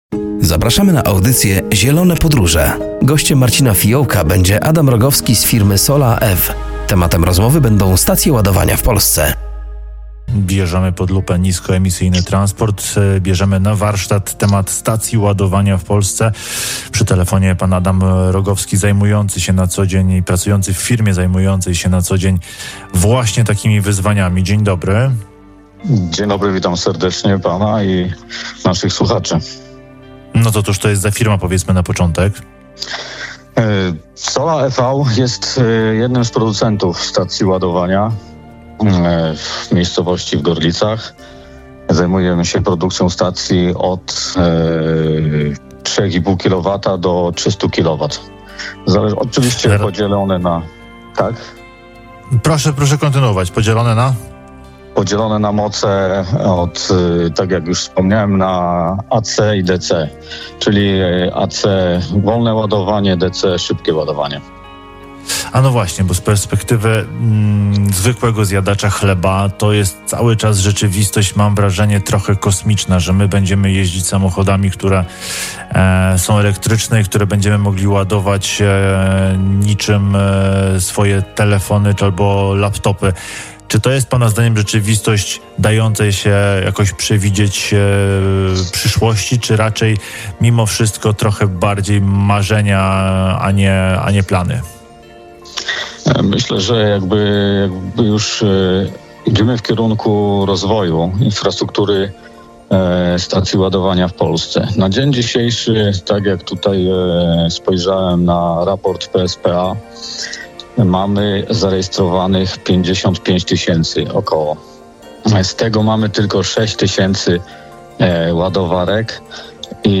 “Zielone Podróże” w środę o g. 10.30 na antenie Radia Nadzieja.